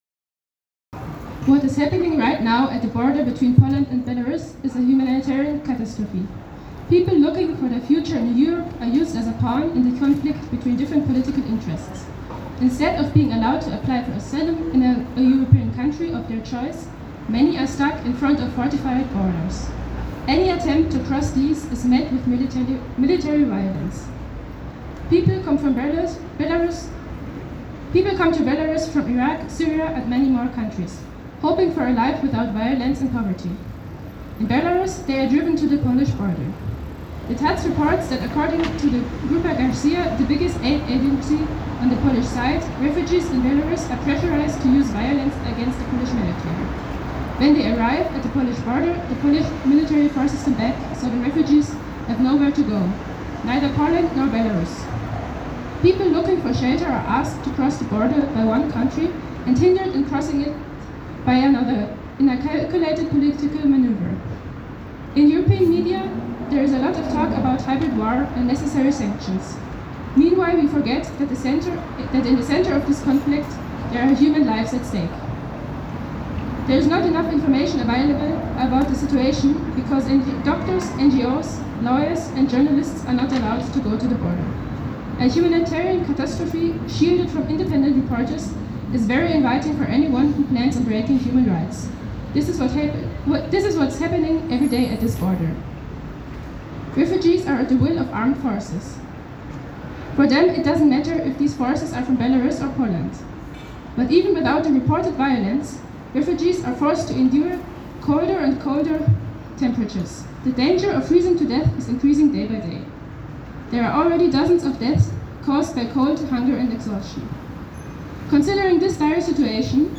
Heute, am Samstag den 20.11.2021 haben sich unter diesem Aufruf bei einer Kundgebung der Seebrücke über 80 Menschen am Europaplatz in Freiburg zusammengefunden.
Redebeiträge der Kundgebung